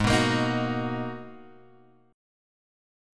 G#m9 chord {4 2 x 3 4 2} chord